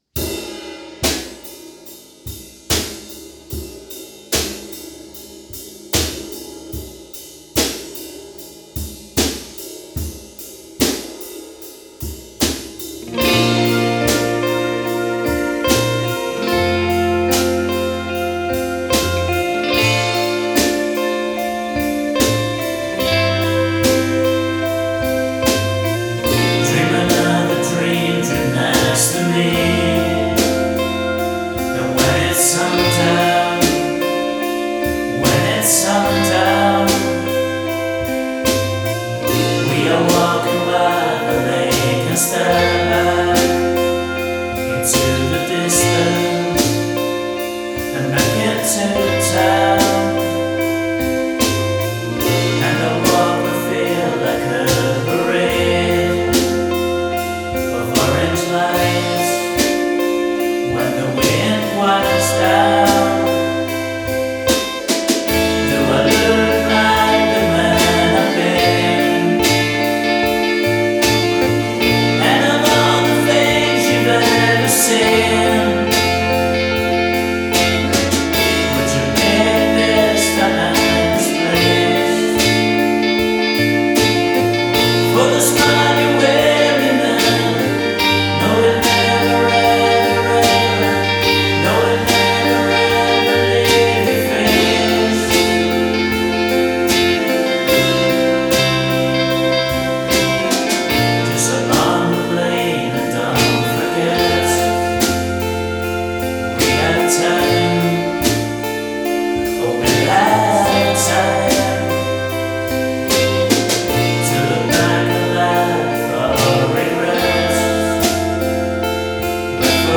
vocals, guitars, bass, keyboards, drums